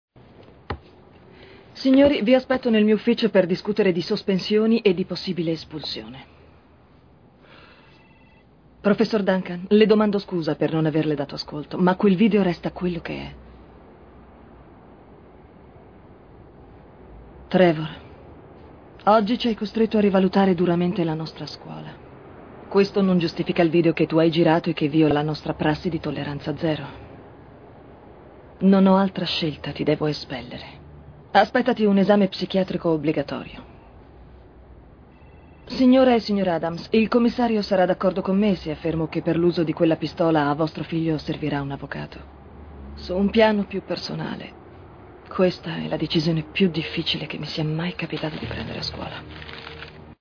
dal film TV "Bang, bang, sei morto!"